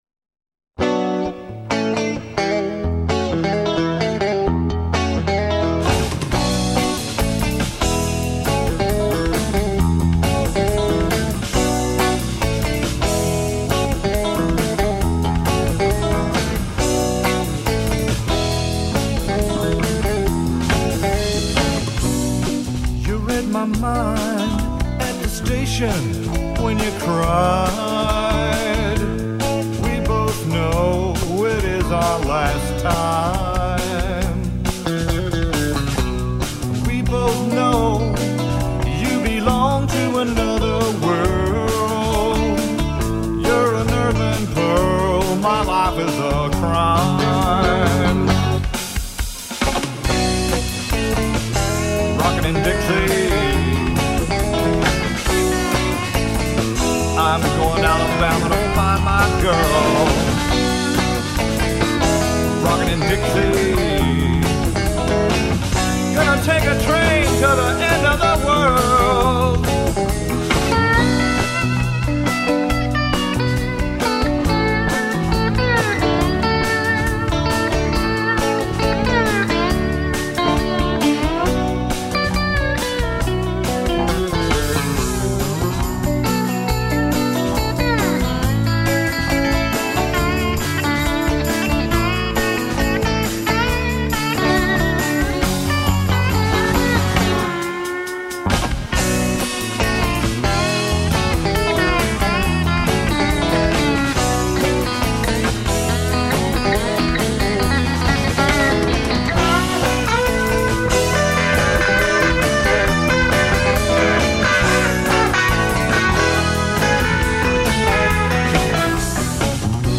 open up a four barrel of V-8 ROCK~A~BILLY RHYTHM & BLUES.